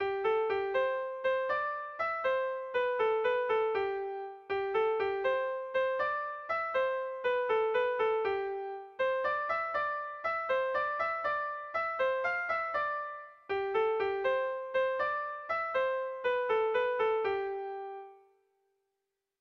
Bertso melodies - View details   To know more about this section
Erlijiozkoa
AABA